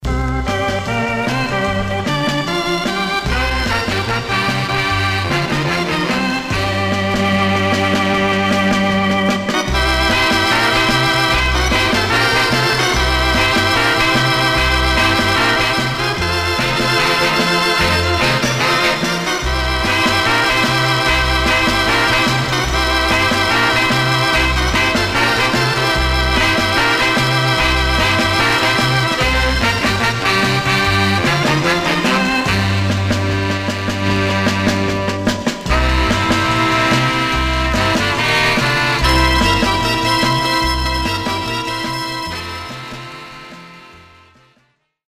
Stereo/mono Mono
Jazz (Also Contains Latin Jazz)